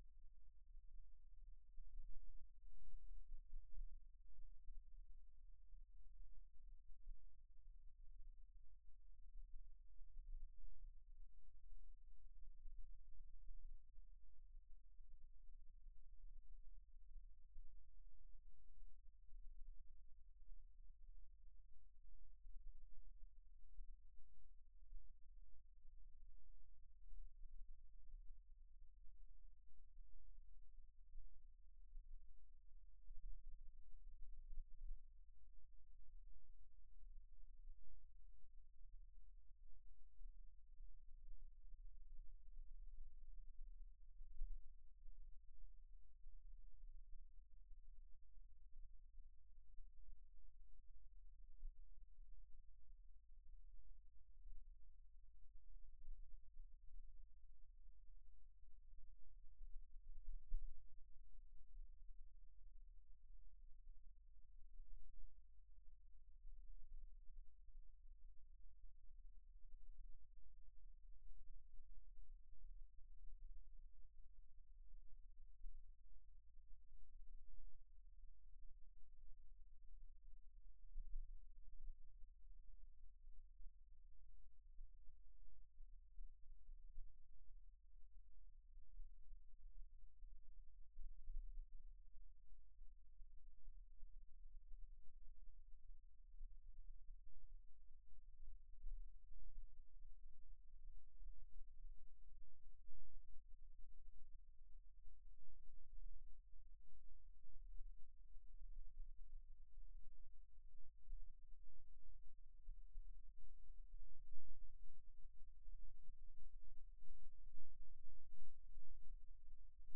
2015-01-08 00:00 to 04:00 and 04:00 to 08:00 at OpenLab st. 04 - Sogliano al Rubicone(FC), N Italy